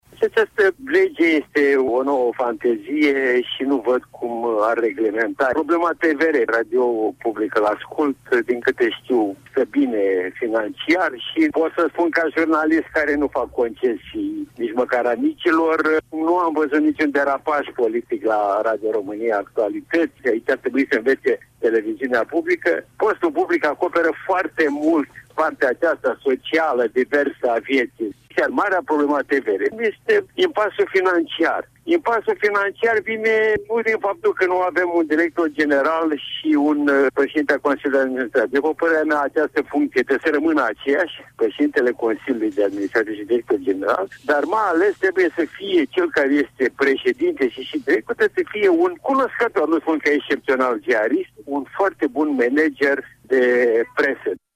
Modificarea Legii de funcţionare a Radioului şi Televiziunii publice nu ar putea duce la rezolvarea problemelor TVR, a declarat la RRA jurnalistul Ion Cristoiu.
El a apreciat în emisiunea „Probleme la zi” de la radio România Actualităţi că actuala formă de organizare a posturilor publice de radio şi tv nu ar trebui modificate.